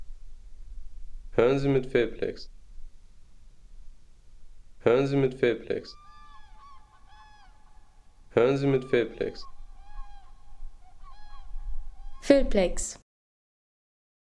Kranich Gesang
Kranich Gesang Home Sounds Tierwelt Vögel Kranich Gesang Seien Sie der Erste, der dieses Produkt bewertet Artikelnummer: 4 Kategorien: Tierwelt - Vögel Kranich Gesang Lade Sound....